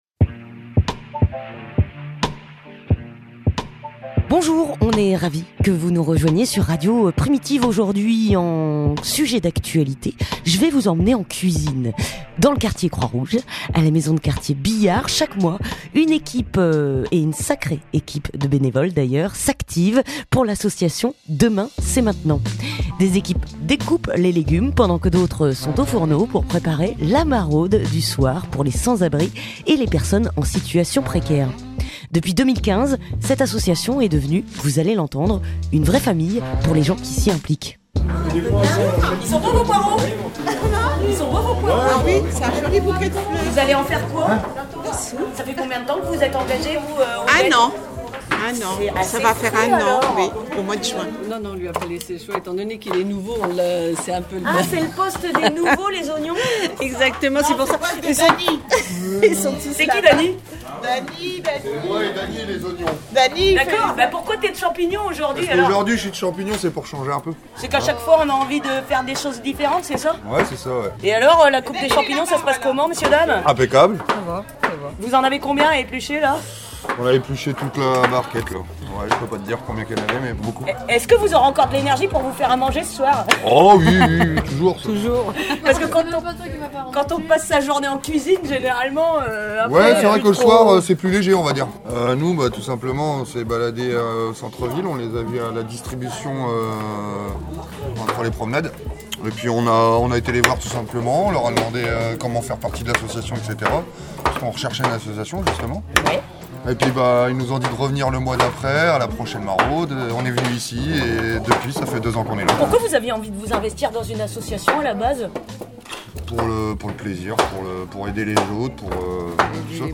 Reportage à Croix-Rouge (16:21)
A la Maison de Quartier Billard-La Nacelle chaque mois, ce sont plus d'une vingtaine de bénévoles qui se retrouvent pour cuisiner dans la bonne humeur.